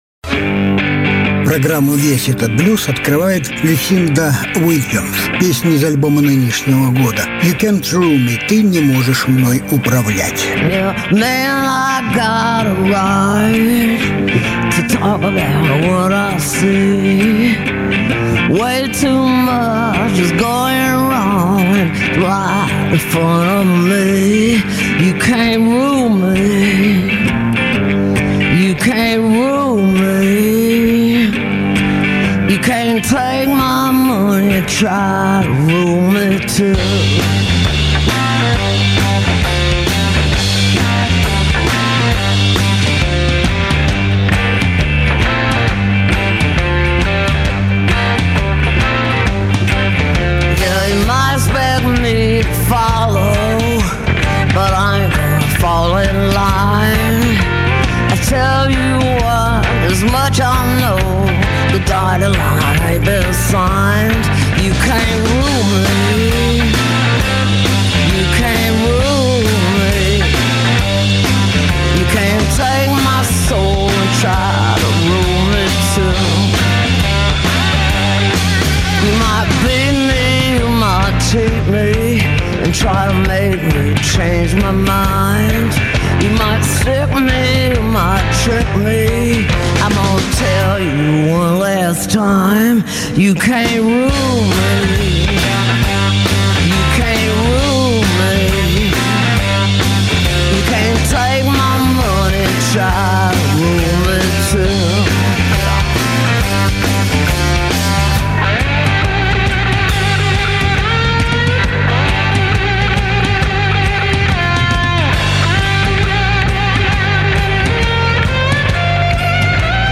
Различные альбомы Жанр: Блюзы и блюзики СОДЕРЖАНИЕ 21.12.2020 1.